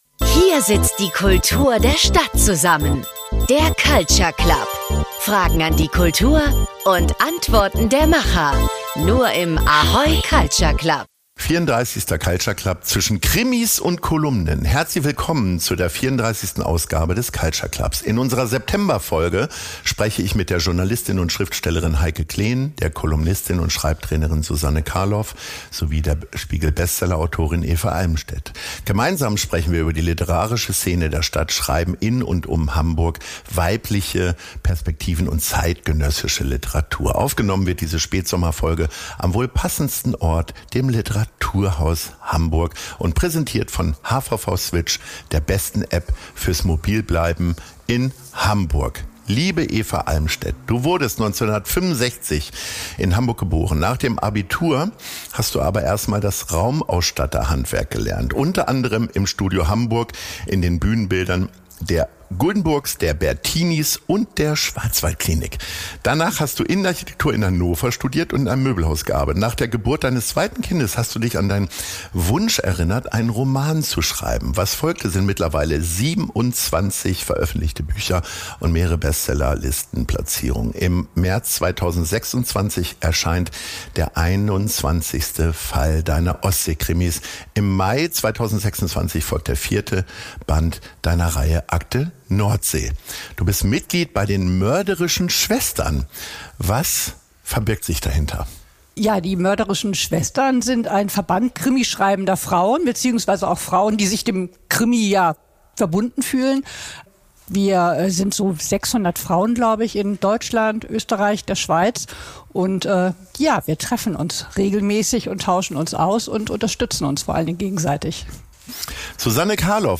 Aufgenommen wurde diese Spätsommer Folge am wohl passendsten Ort: dem Literaturhaus Hamburg und präsentiert von HVV Switch, der besten App fürs mobil bleiben in Hamburg .